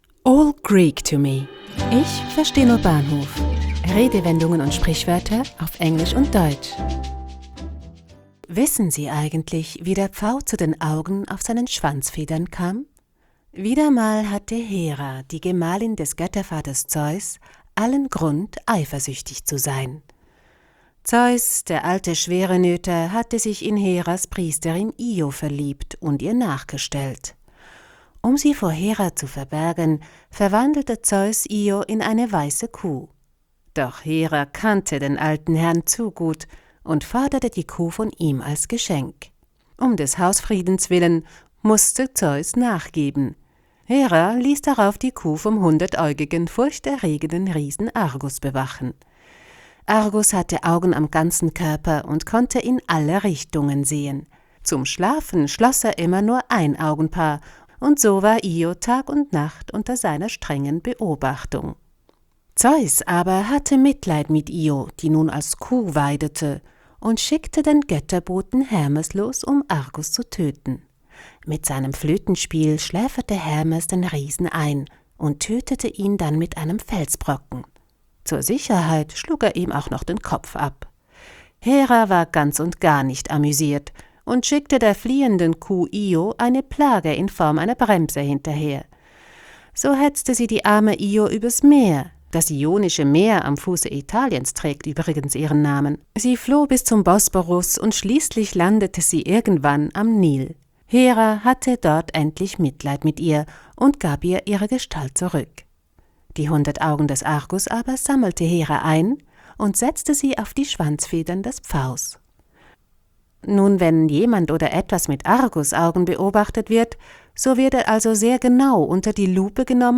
Sprecherin